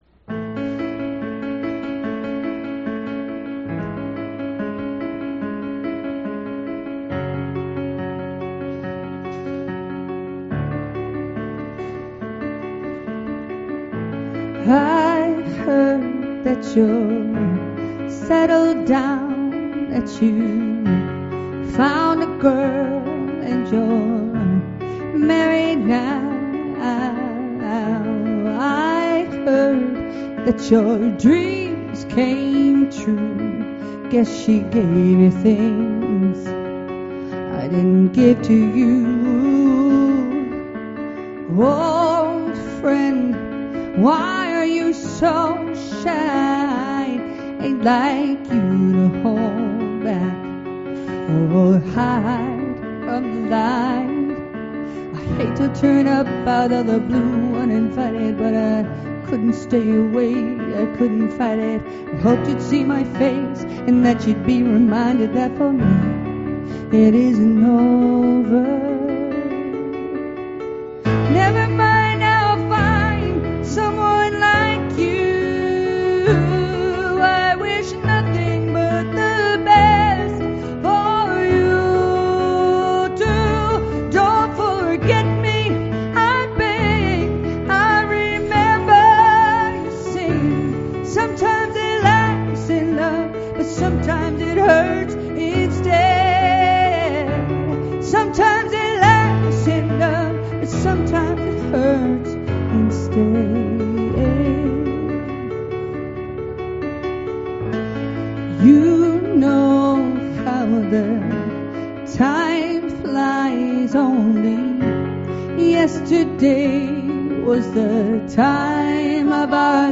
Here are a few live clips from some of our recent shows.